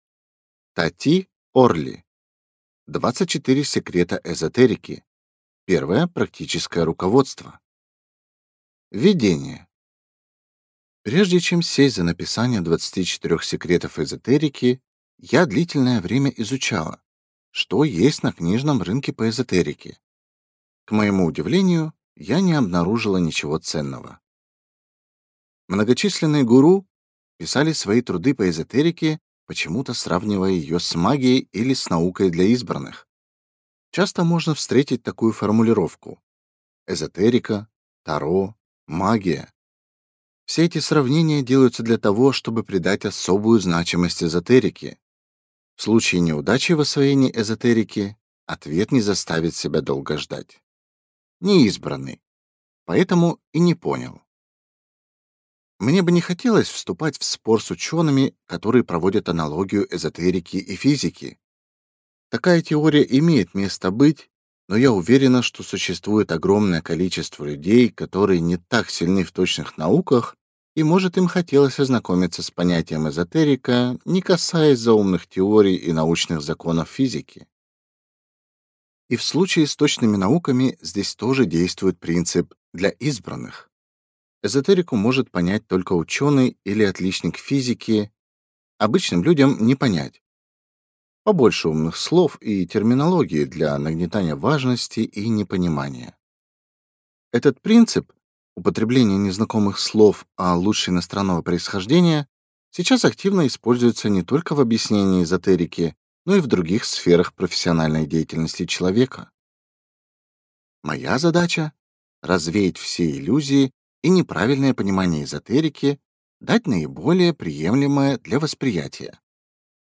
Аудиокнига 24 секрета эзотерики | Библиотека аудиокниг